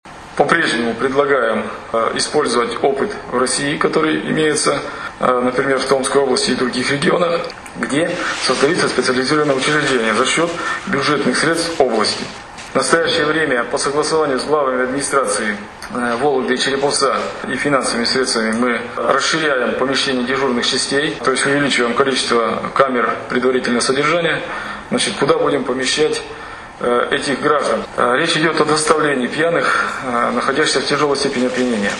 Об этом, в частности, в ходе очередной сессии областного Законодательного Собрания заявил начальник УМВД РФ по Вологодской области Виталий Федотов.
Виталий Федотов рассказывает об открытии вытрезвителей